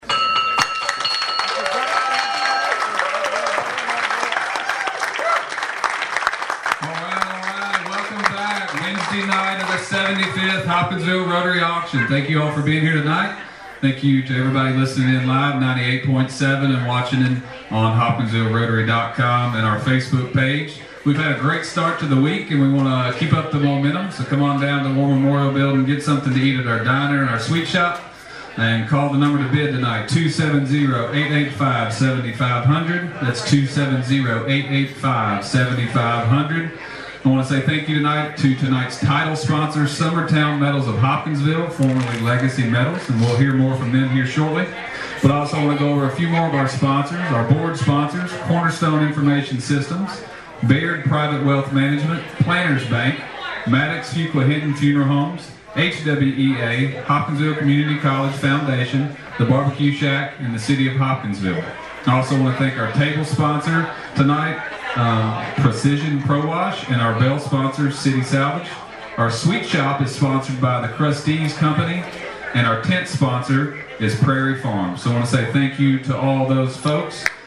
Always looking to raise “one more dollar than Cadiz,” Wednesday night’s efforts at the 75th Annual Hopkinsville Rotary Auction inside the War Memorial Building helped the club move well past $200,000 in fundraising.